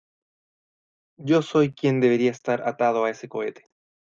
Pronounced as (IPA) /ˈsoi/